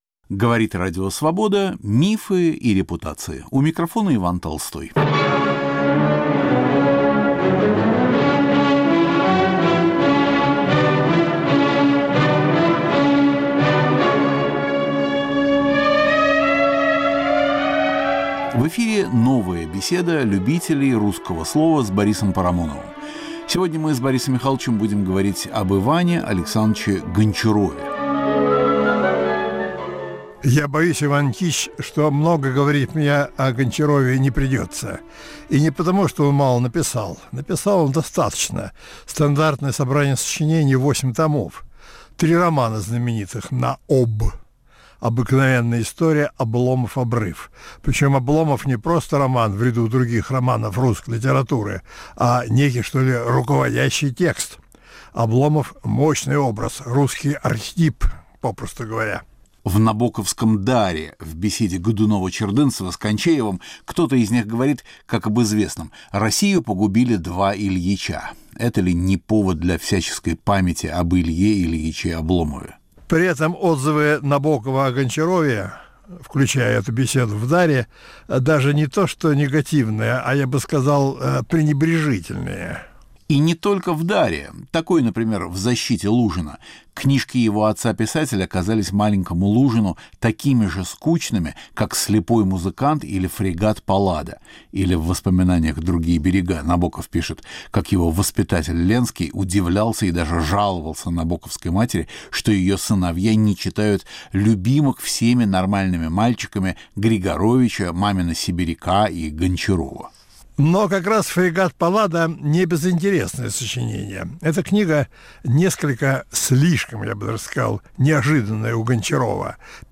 Беседа любителей русского слова с Борисом Парамоновым. Разговор об Иване Гончарове. Почему русские классики не жаловали "Обломова"?